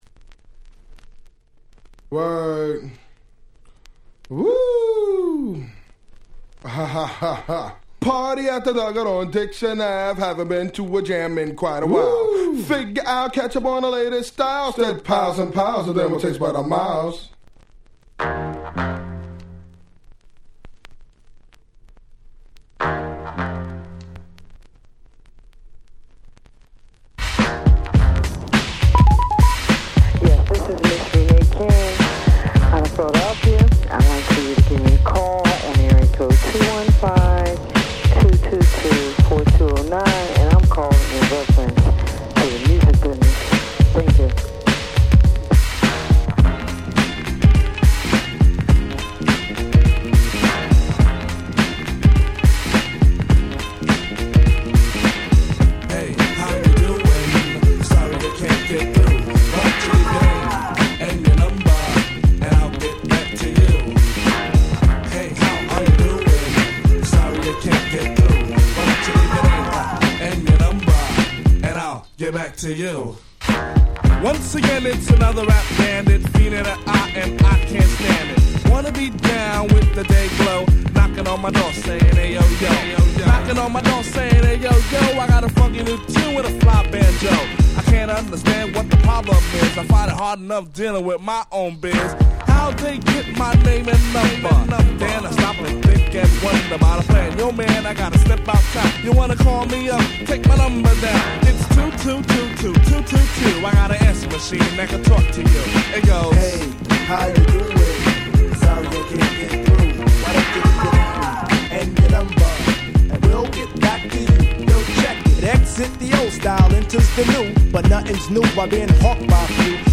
91' Smash Hit Hip Hop !!
Boom Bap ブーンバップ